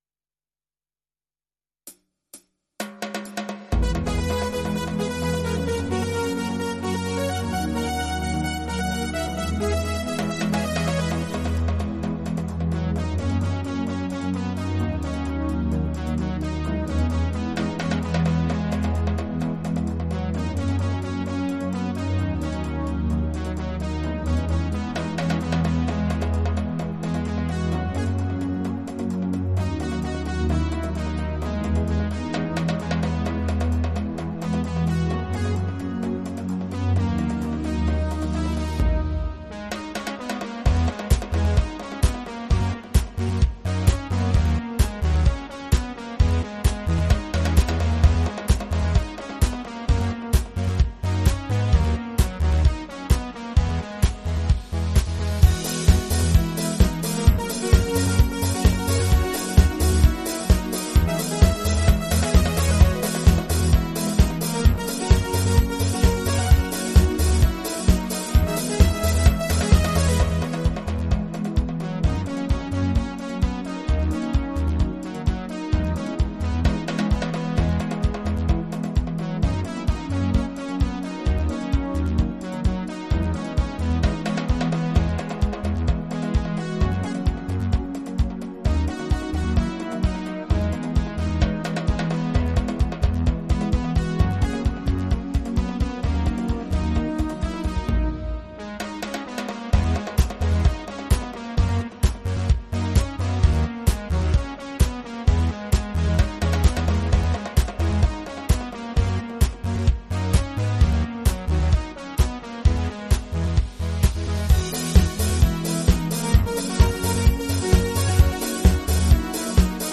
version instrumentale multipistes
au format MIDI Karaoke pro.